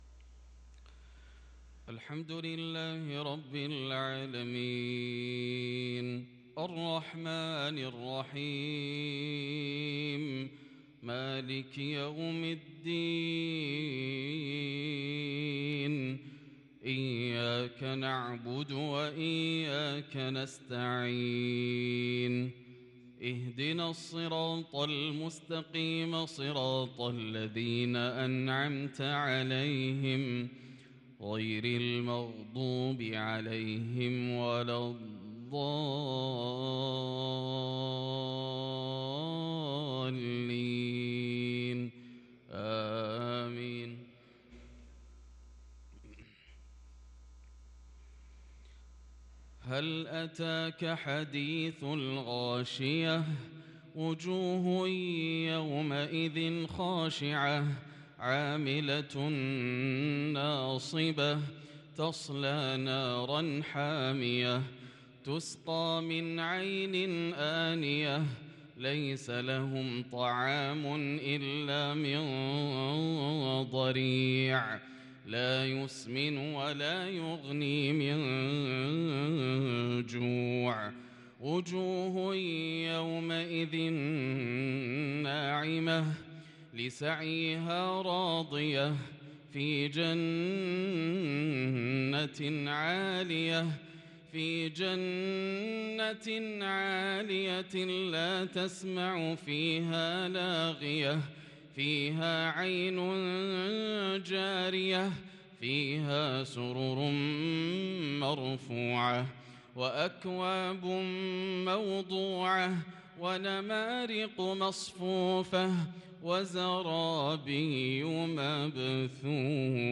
تـلاوة بديعة لسورة الغاشية - مغرب الأحد 5-4-1444هـ > عام 1444 > الفروض - تلاوات ياسر الدوسري